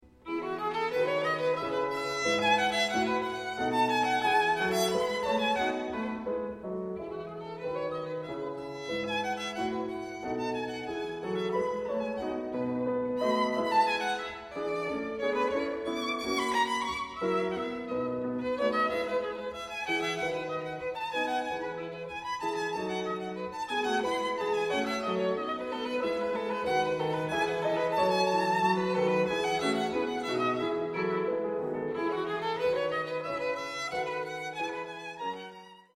アダージョ
このヴァイオリンソナタは1722年頃、ヴァイオリンと通奏低音のためのソナタ作品1の中の6番目のソナタとして発表された。